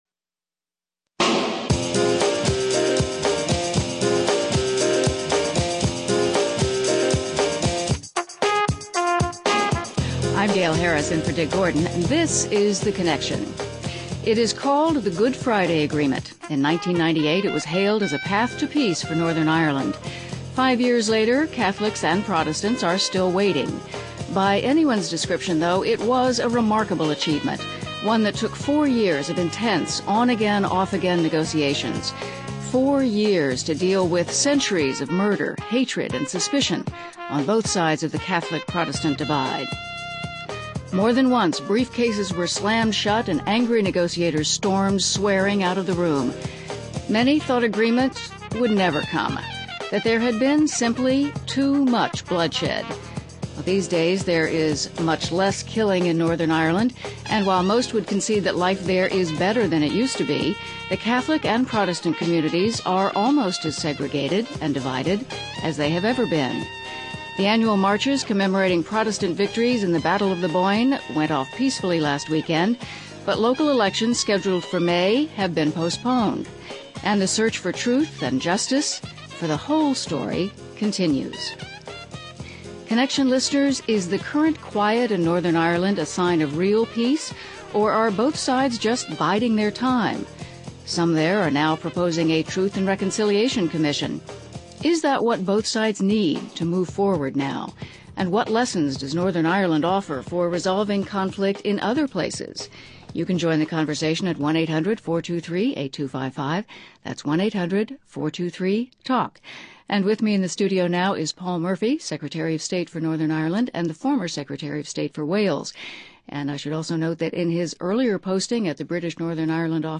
But where some see signs of peace, others believe deep sectarian divides remain, along with wounds that politicians alone cannot heal. A conversation with Paul Murphy, the British Secretary of State for Northern Ireland, on reconciling the past and marching forward.